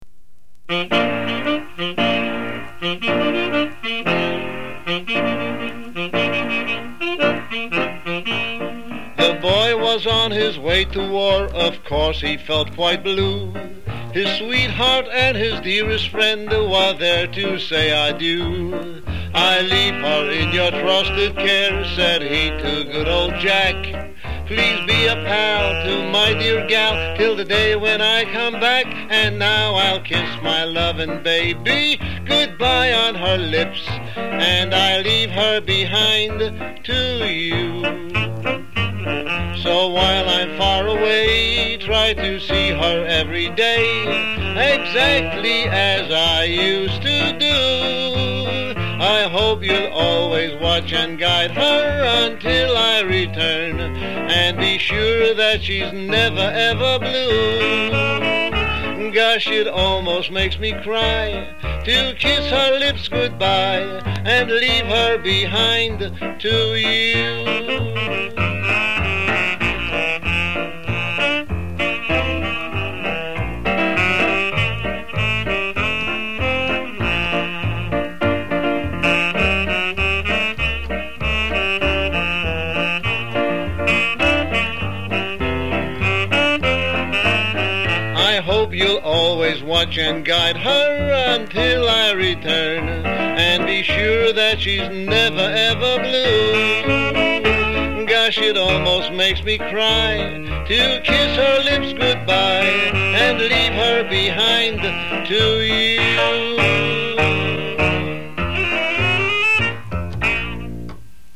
double entendre party record